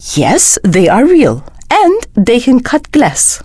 DraeneiFemalePissed09.wav